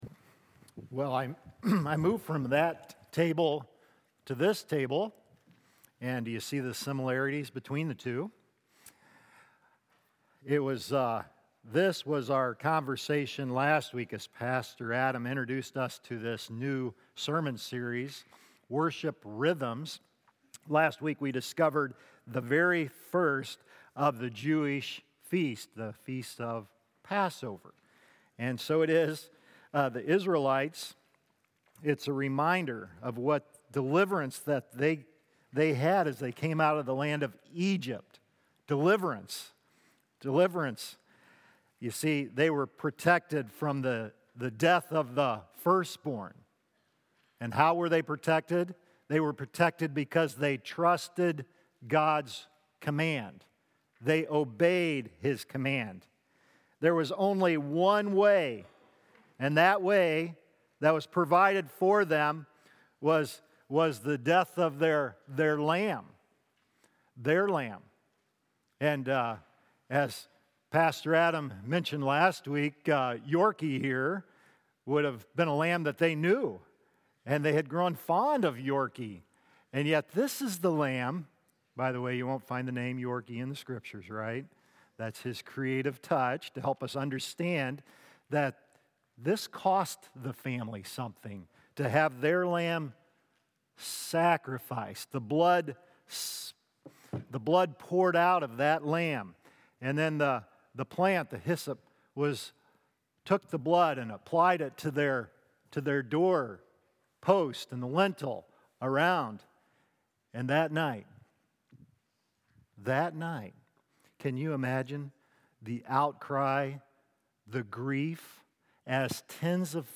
Worship Rhythms: Feast of Unleavened Bread | Baptist Church in Jamestown, Ohio, dedicated to a spirit of unity, prayer, and spiritual growth